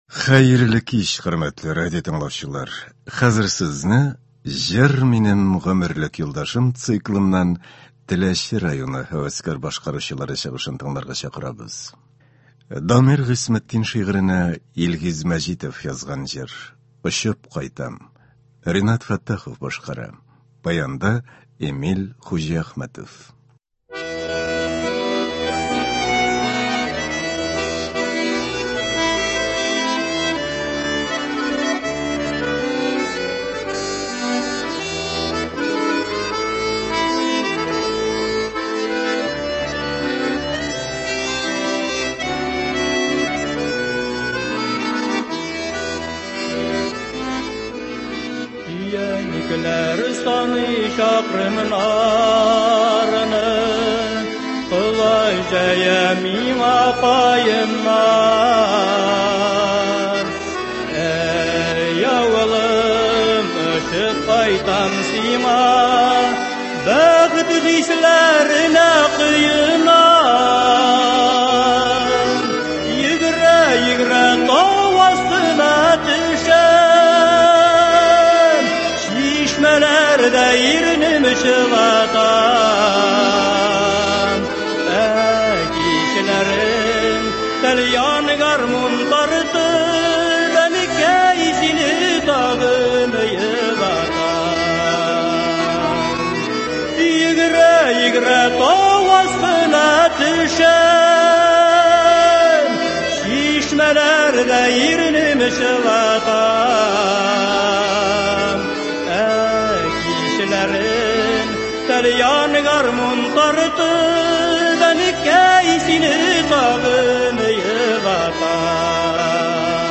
Үзешчән башкаручылар чыгышы.
Концерт (29.04.24)